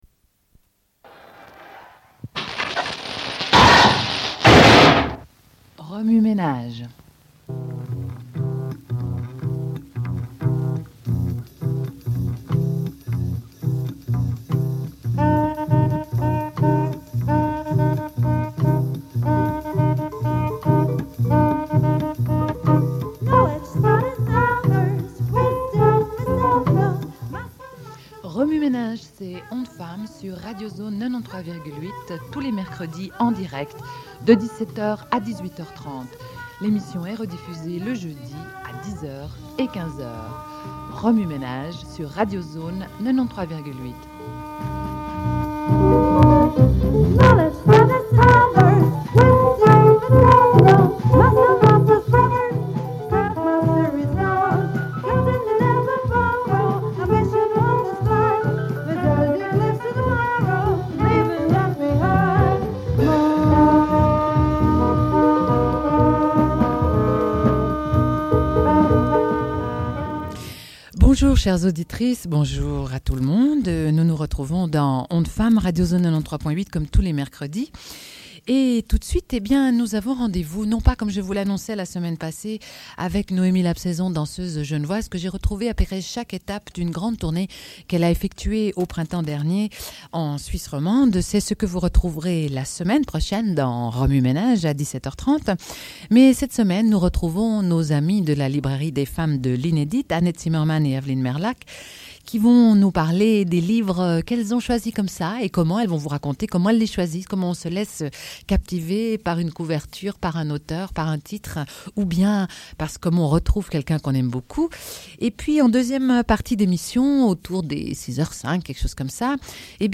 Une cassette audio, face A31:10